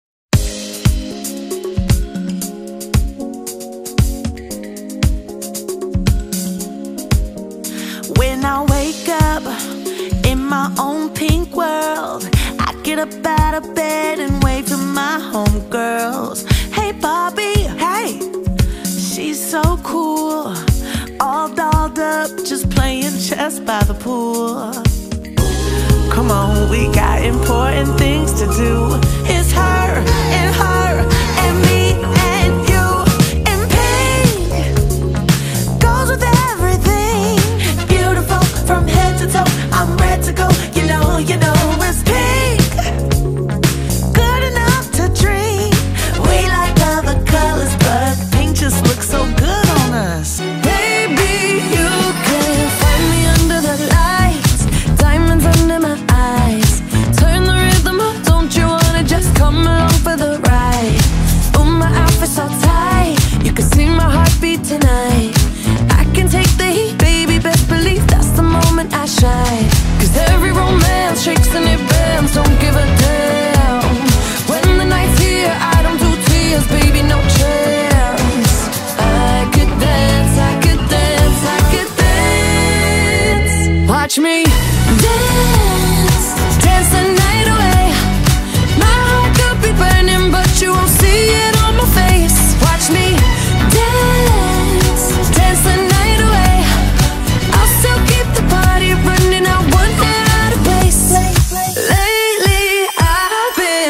Soundtrack, Pop